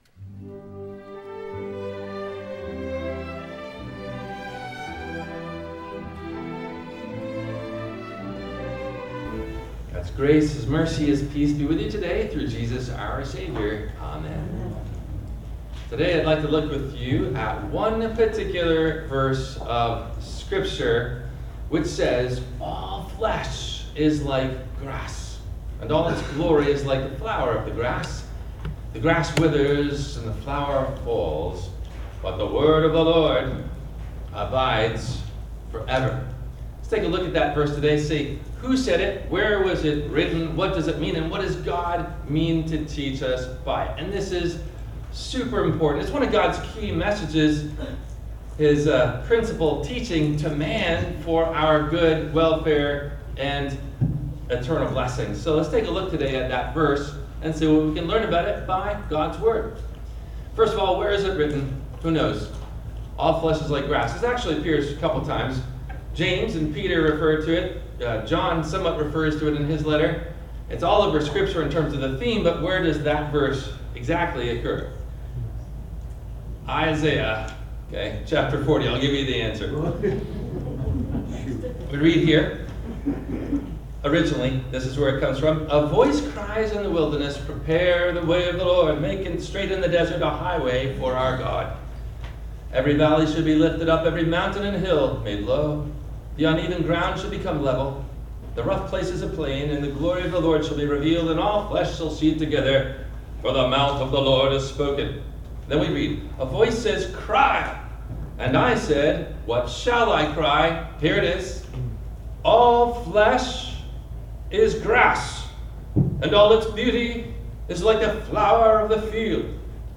What’s God tell us to Pursue in Life … the Temporary or Eternal? – WMIE Radio Sermon – September 01 2025